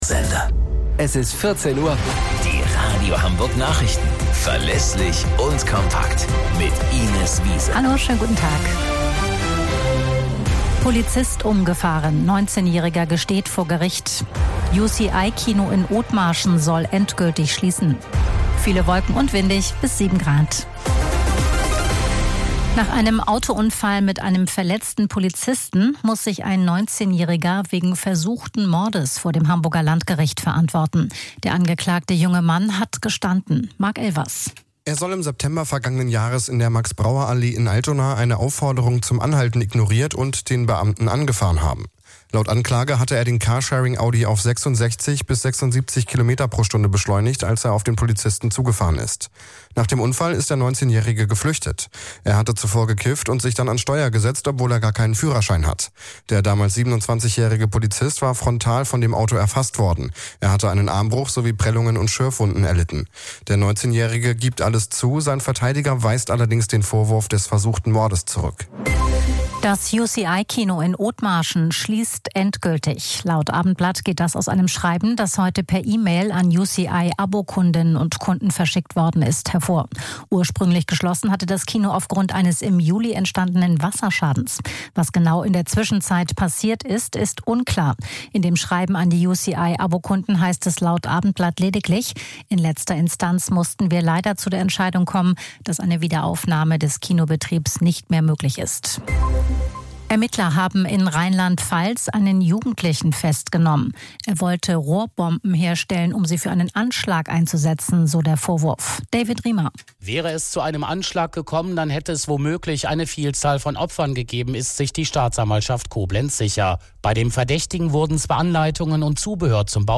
Radio Hamburg Nachrichten vom 28.11.2024 um 21 Uhr - 28.11.2024